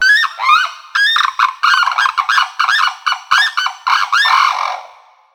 Unison Call | A duet performed by a pair, to strengthen their bond and protect their territory.
Wattled-Crane-Unison.mp3